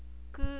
ku.wav